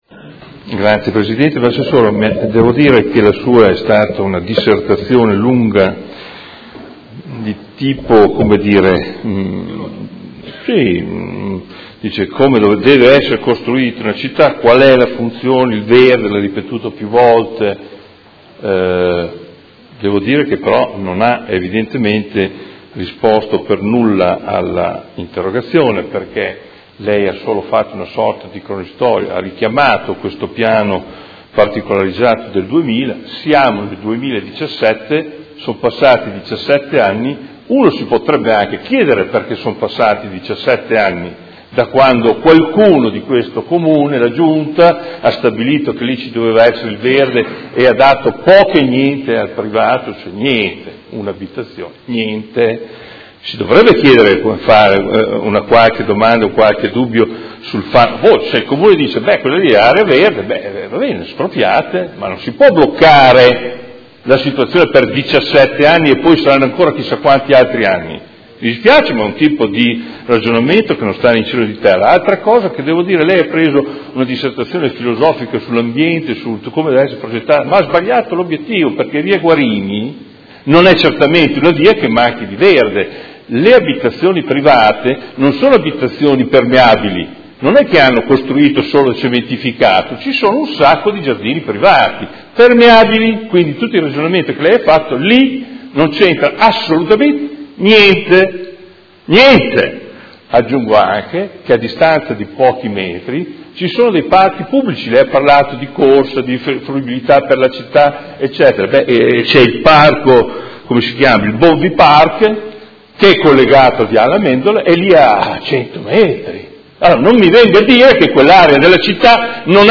Seduta del 20/04/2017. Conclude interrogazione del Consigliere Morandi (FI) avente per oggetto: L’area dell’ex cinema Ariston è ancora in una situazione di forte degrado; occorre che sia proposta dall’Assessorato competente una soluzione che metta fine al degrado e consenta il recupero e la rinascita del comparto